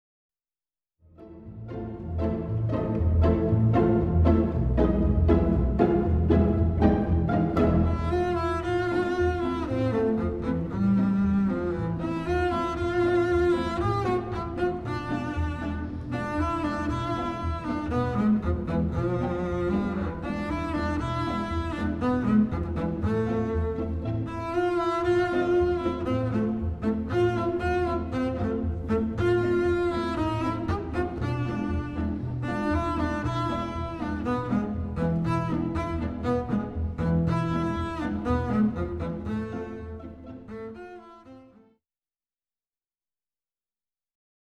Excerpts are from the orchestra version.